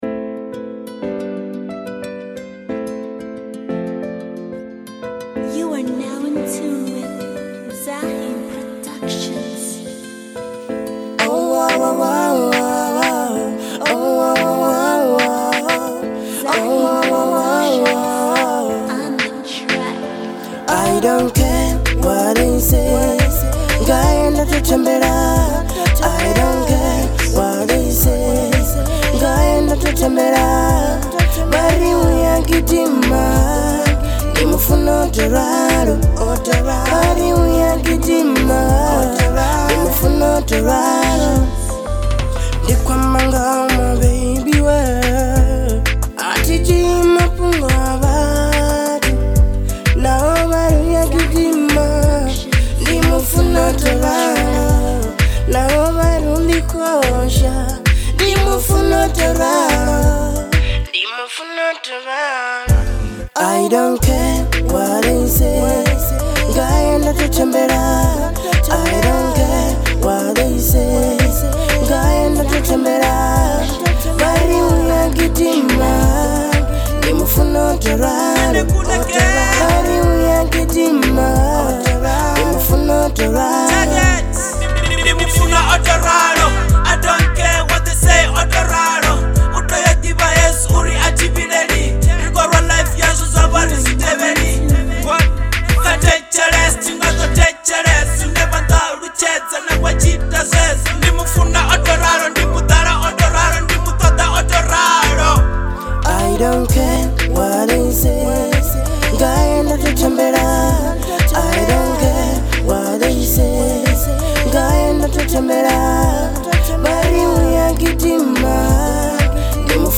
Genre : Venrap